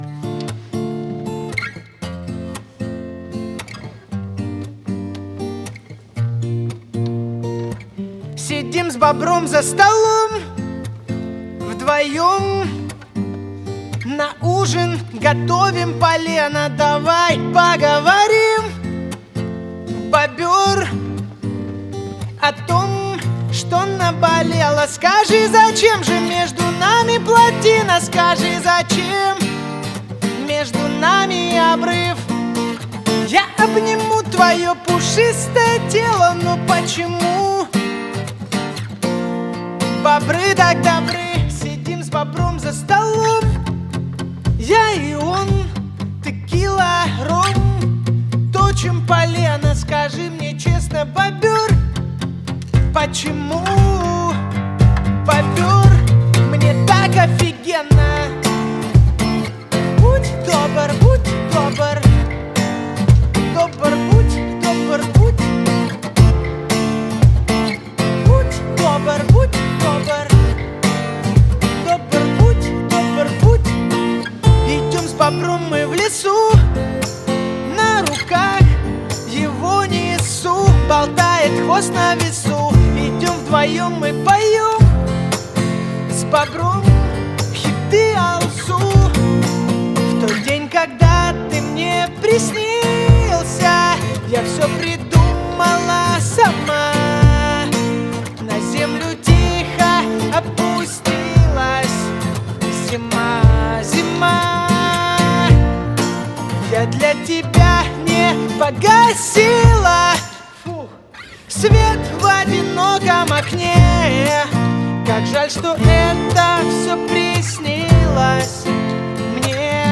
Акустическая версия